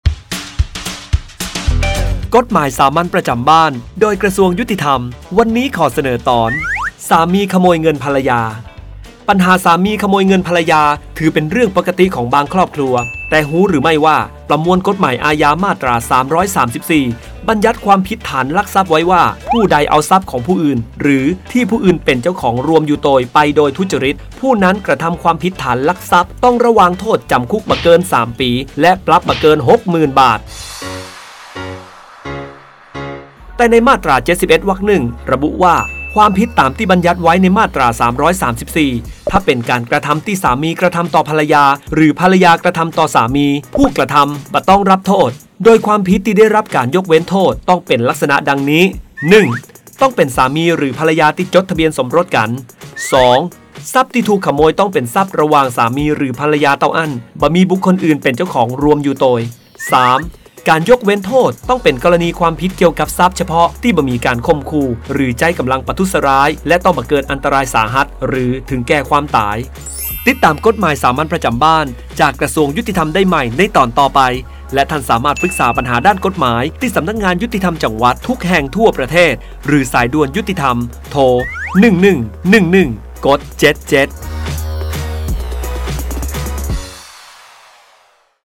กฎหมายสามัญประจำบ้าน ฉบับภาษาท้องถิ่น ภาคเหนือ ตอนสามีขโมยเงินภรรยา
ลักษณะของสื่อ :   บรรยาย, คลิปเสียง